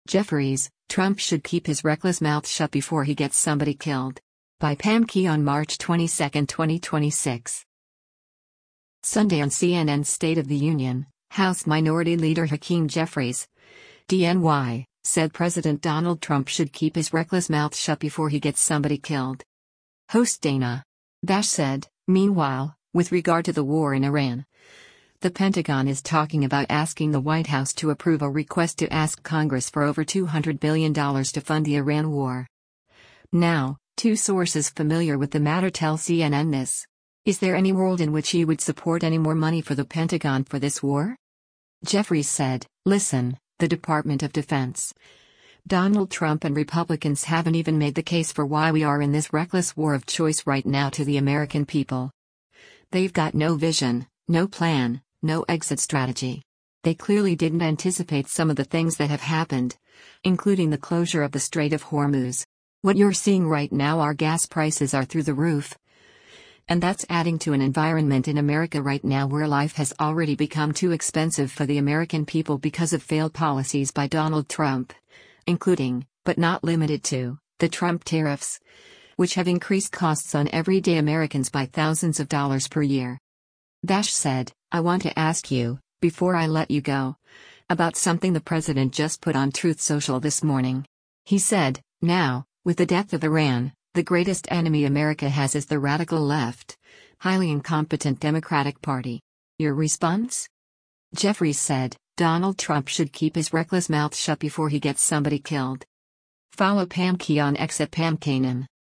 Sunday on CNN’s “State of the Union,” House Minority Leader Hakeem Jeffries (D-NY) said President Donald Trump “should keep his reckless mouth shut before he gets somebody killed.”